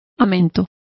Complete with pronunciation of the translation of catkin.